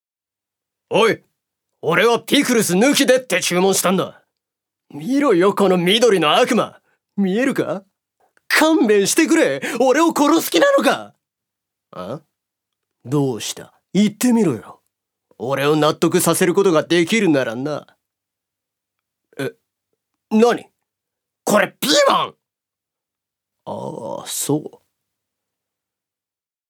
所属：男性タレント
セリフ４